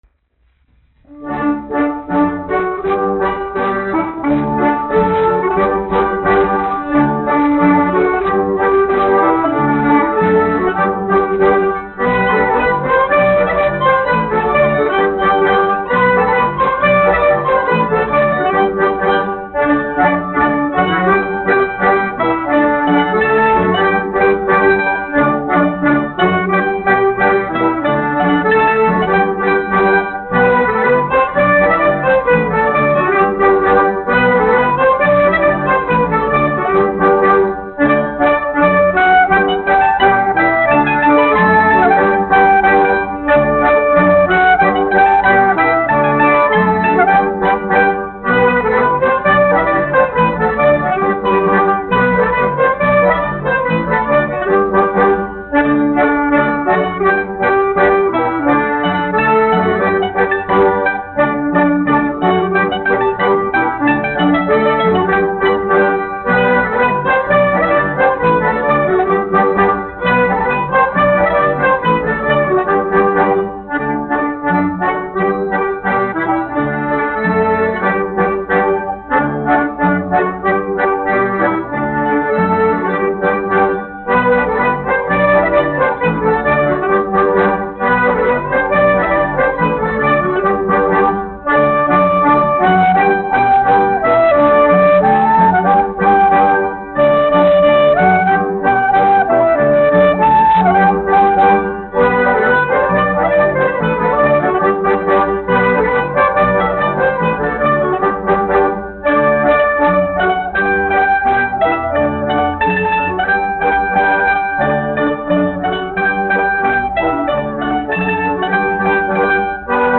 Zvejnieks mani aicināja : tautas deja
Alfrēda Vintera Jautrā kapela (mūzikas grupa), izpildītājs
1 skpl. : analogs, 78 apgr/min, mono ; 25 cm
Latviešu tautas dejas
Skaņuplate